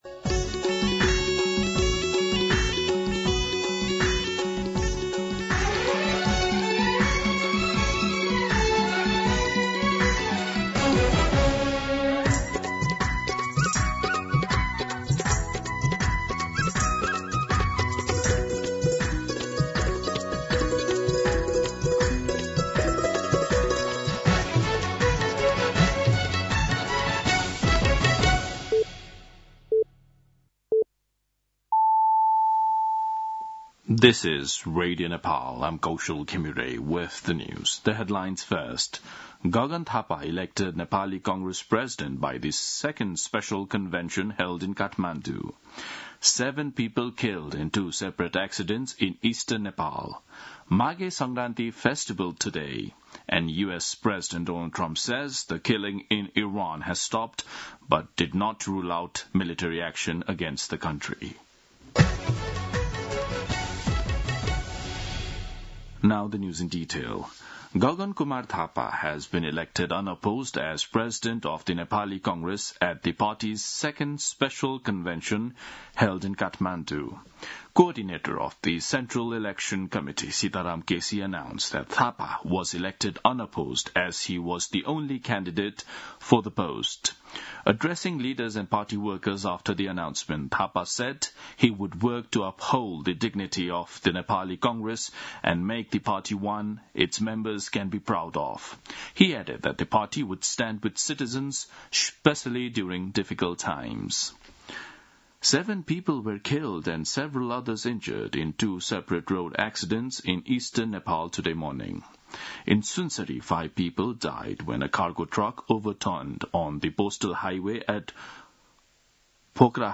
दिउँसो २ बजेको अङ्ग्रेजी समाचार : १ माघ , २०८२
2-pm-English-News-1.mp3